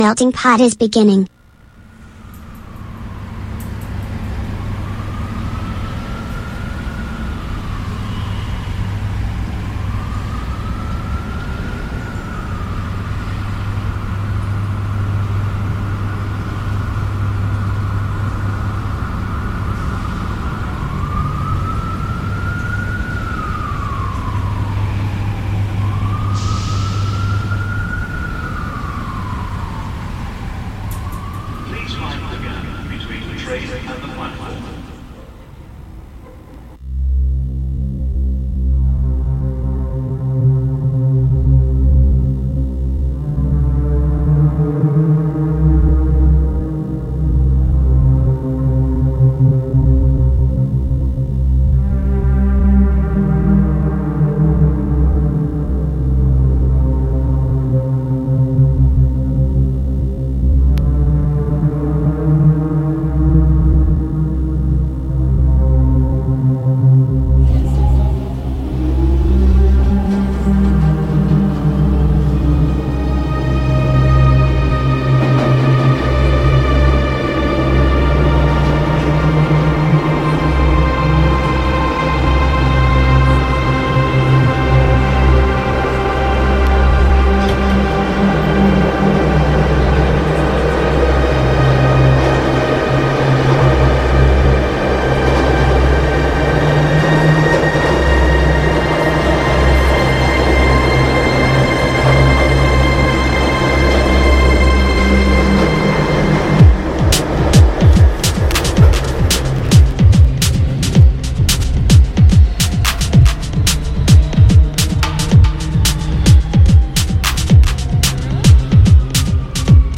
MUSICA NOTIZIE INTERVISTE A MELTINGPOT | Radio Città Aperta